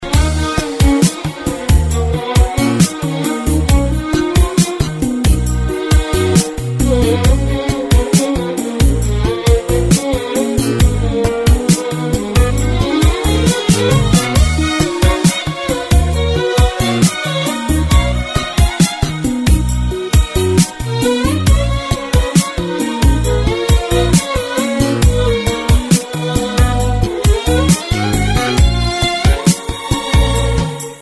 رینگتون احساسی و رمانتیک بی کلام